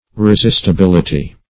Resistibility \Re*sist`i*bil"i*ty\ (-?-b?l"?-t?), n..